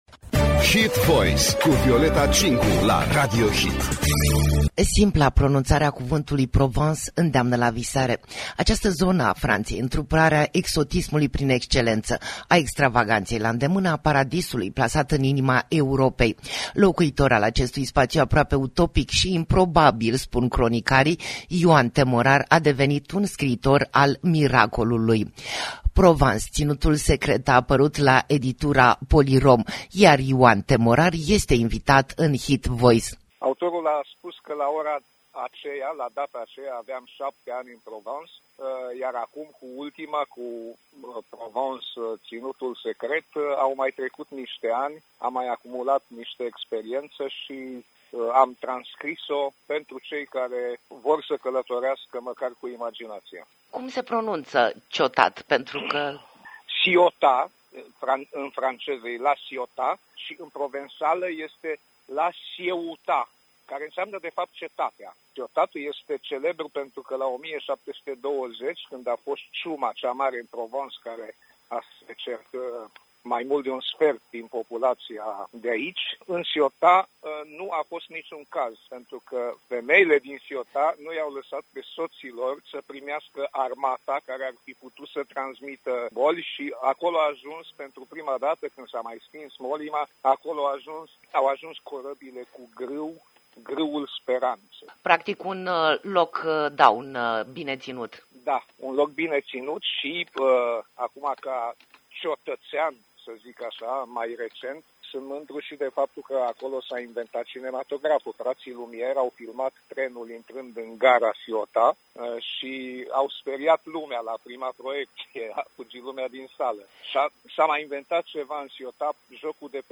Radio HIT l-a provocat pe Ioan T Morar la un pahar de vorbă pe axa Iași- La Ciotat